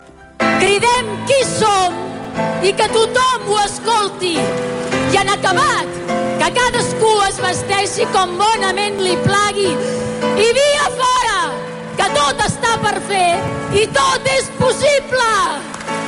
Fa uns minuts en un debat entre PILAR RAHOLA i ALBERT RIVERA, a RAC1, el RIVERA ha dit: